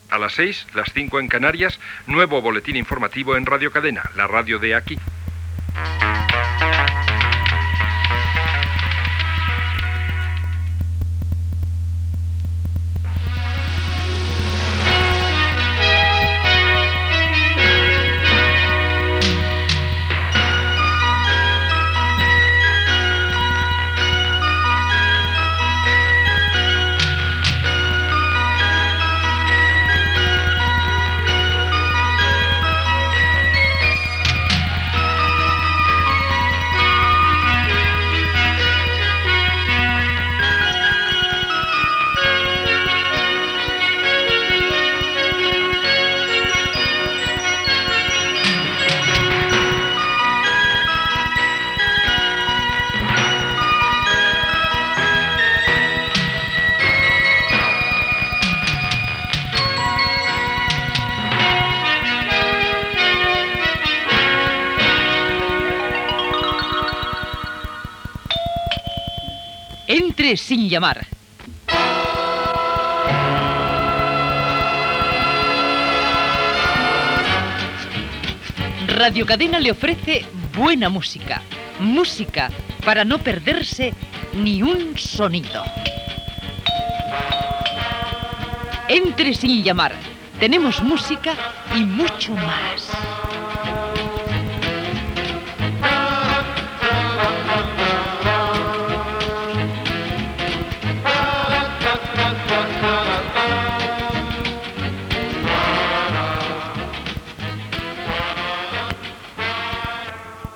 Final butlletí, sintonia de l'emissora i inici del programa.
Musical